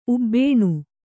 ♪ ubbēṇu